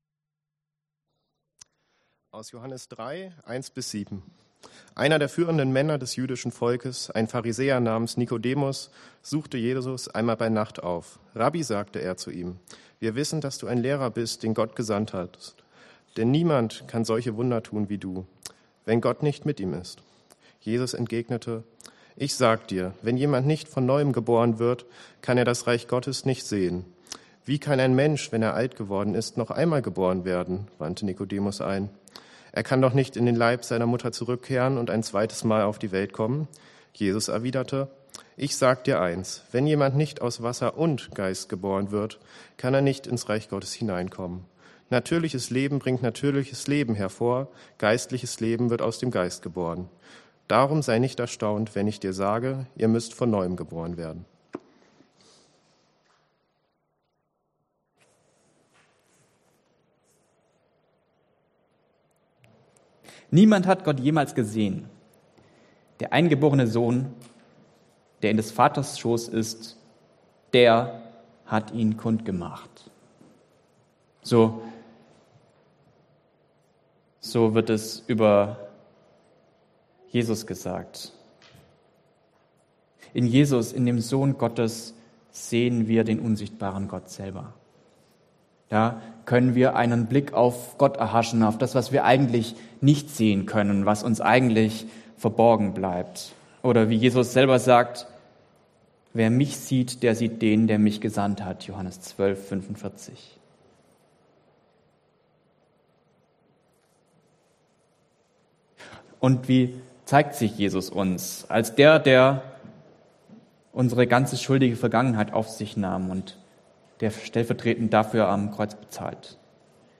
Gesamter Gottesdienst ohne Zeugnisse & Abkündigungen
Passage: Johannes 3 Dienstart: Gottesdienst